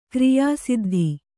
♪ kriyā siddhi